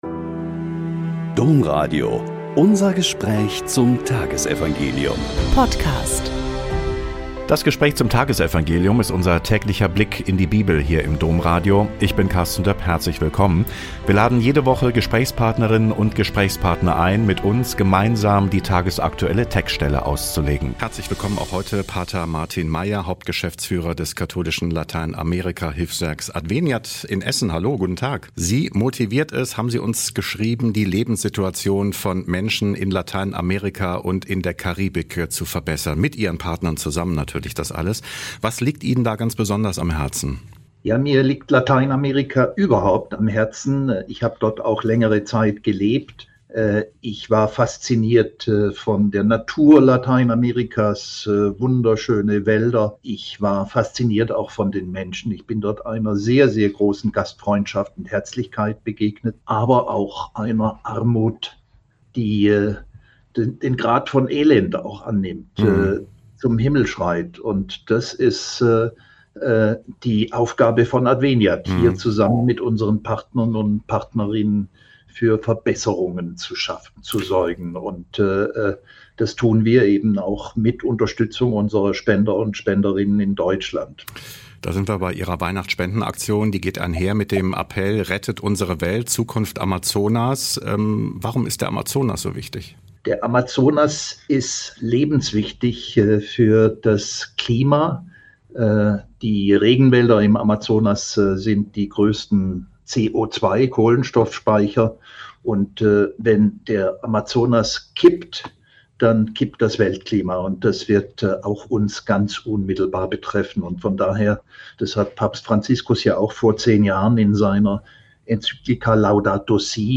Mt 11,28-30 - Gespräch